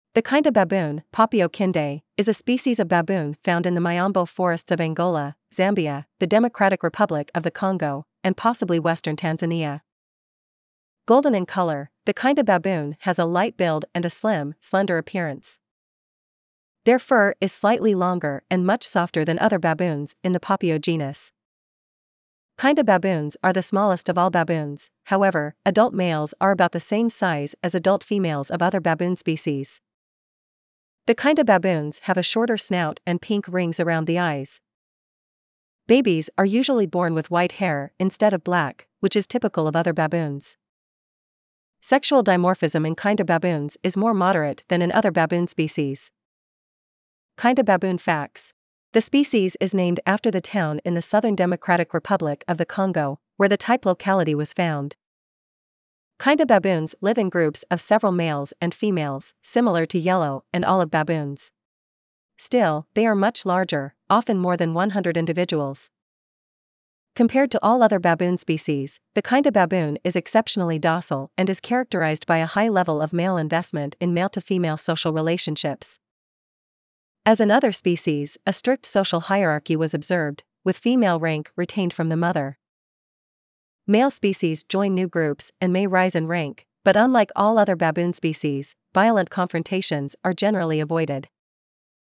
Kinda Baboon
Kinda-Baboon.mp3